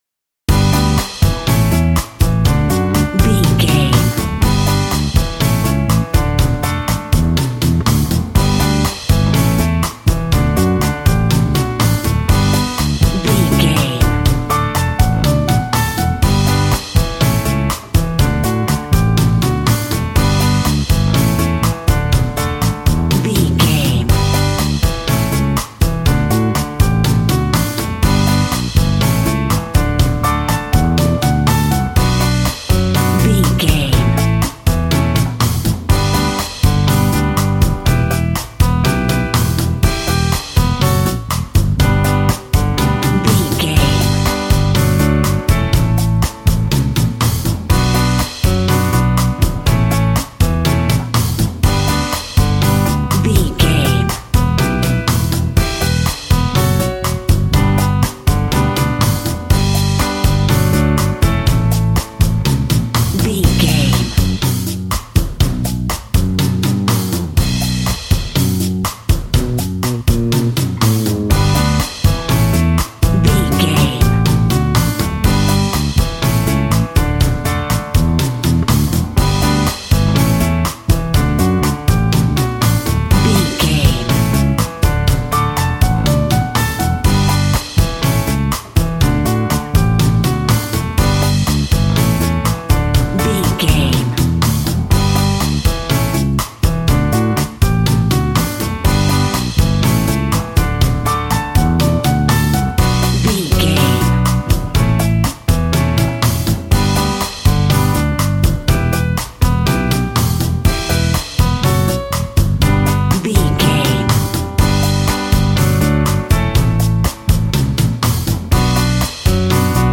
Aeolian/Minor
funky
energetic
romantic
percussion
electric guitar
acoustic guitar